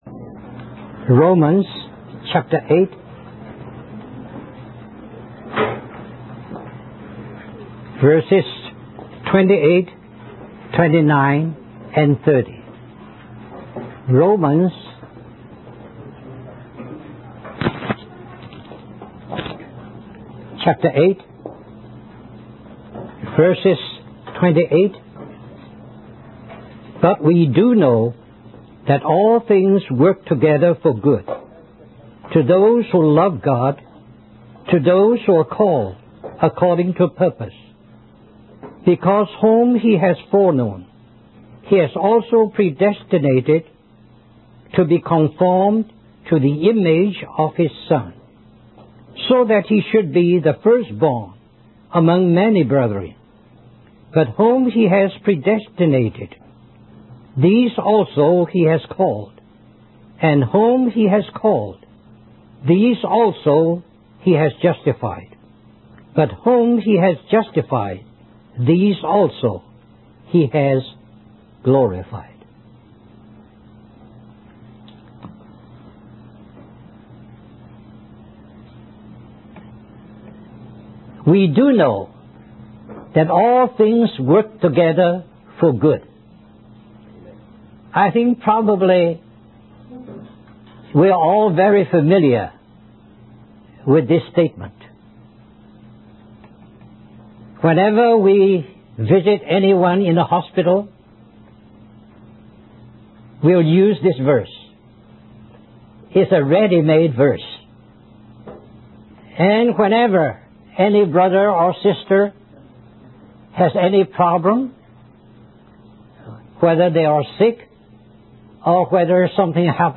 In this sermon, the speaker emphasizes that God is the one who controls and orchestrates all things in our lives with a positive and definite purpose.